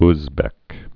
(zbĕk, ŭz-)